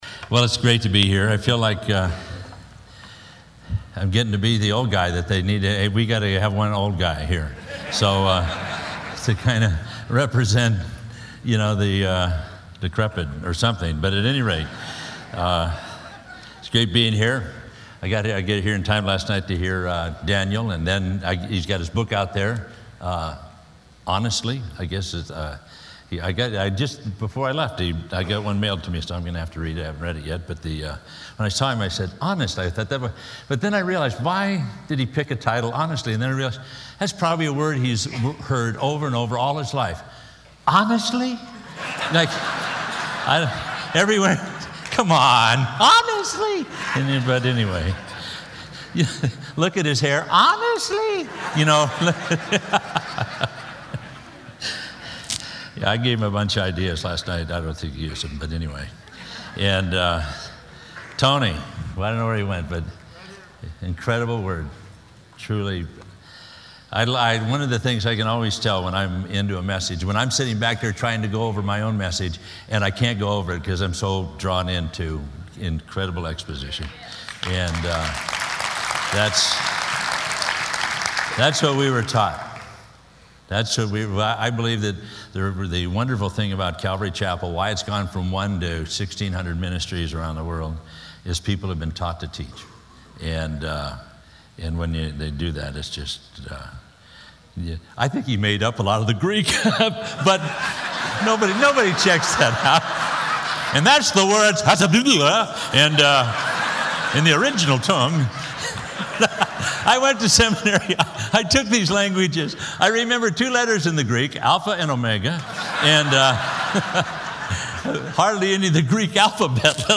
at the 2016 SW Pastors and Leaders Conference